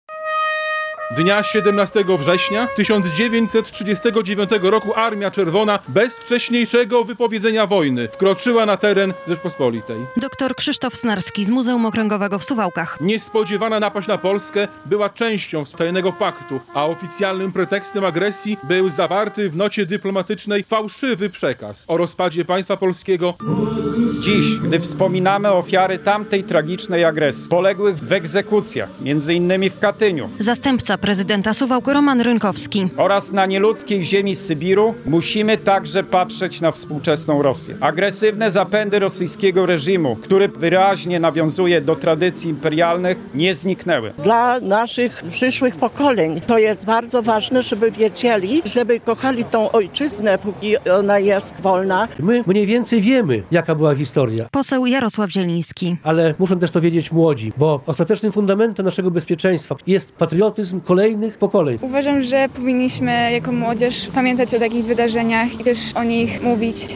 Uroczystości w Suwałkach - relacja
Zabrzmiał hymn państwowy, głos zabrali przedstawiciele władz miasta i zaproszeni goście, a pod pomnikiem złożono kwiaty.